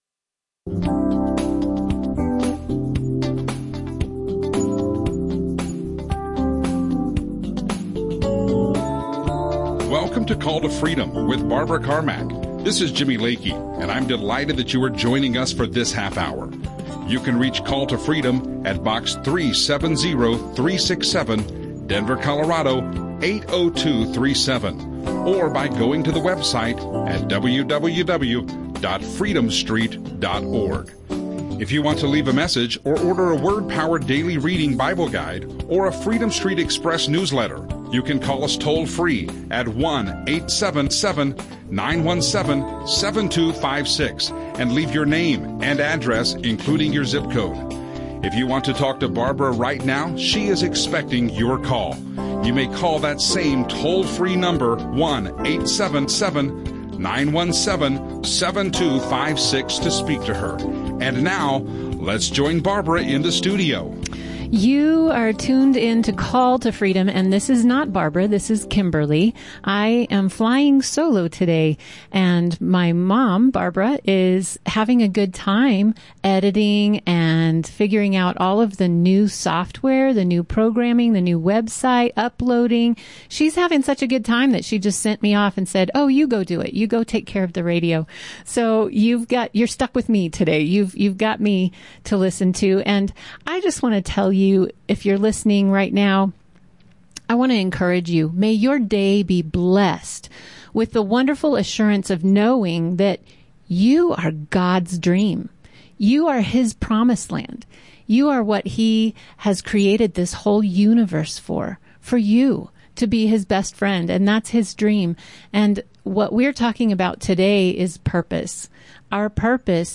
Christian talk
radio show